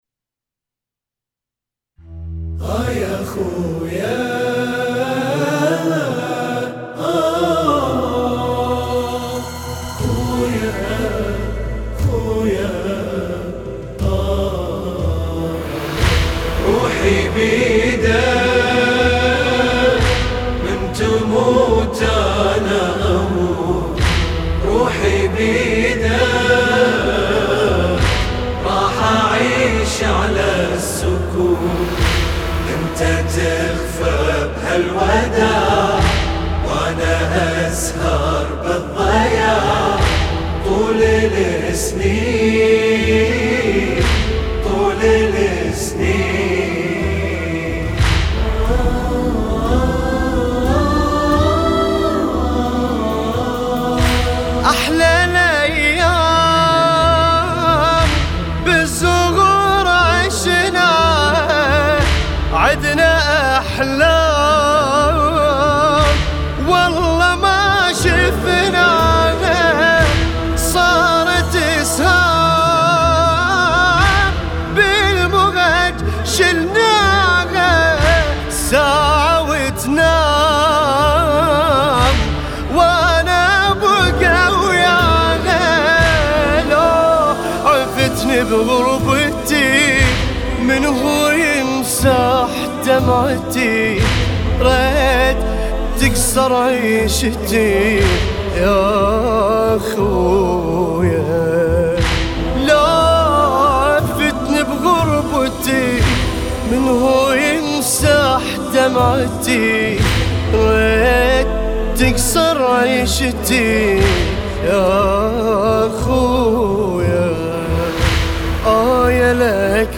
لطمية